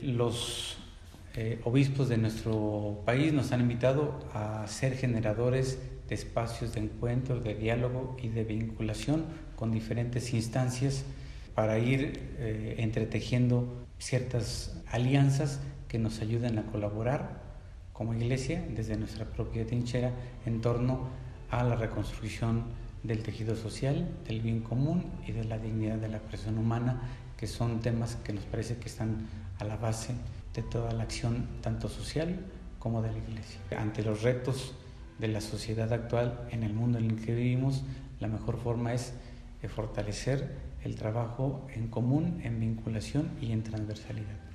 Enrique Díaz Díaz – Obispo de la Diócesis de Irapuato